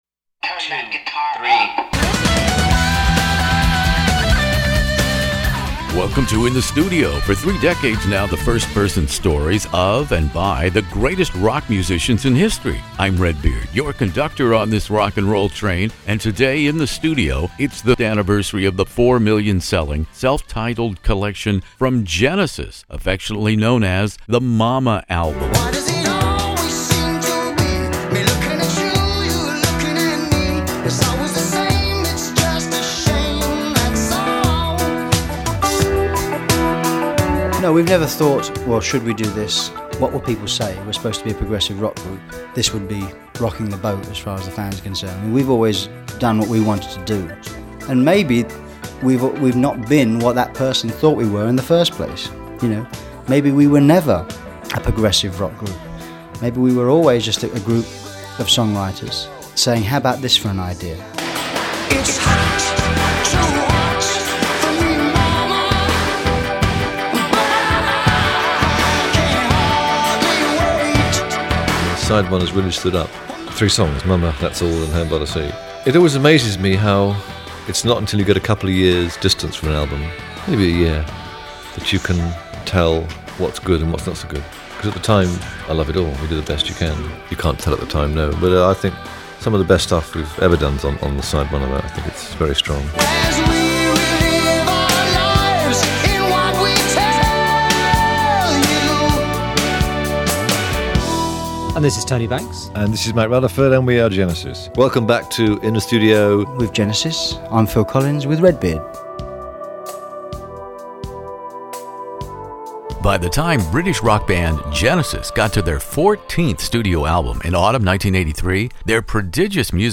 Prepping for another significant album interview, specifically the quadruple platinum Genesis (’83), the sheer prolific output of the English progressive rock band became apparent: Genesis was already in double figures for studio album releases by the early Eighties!